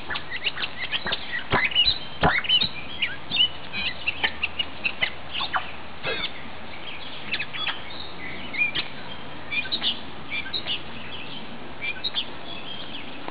around turtle pond i could hear birds singing in the canopy. they were hard to spot, but i did see a yellow bird, which was too large to be a warbler or a goldfinch. turned out it was a scarlet tanager, a female, which are different from the male in that they're yellow instead of red. there were also
mockingbirds singing loudly.
mockingbird.wav